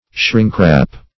shrink-wrap \shrink"-wrap\, shrinkwrap \shrink"wrap\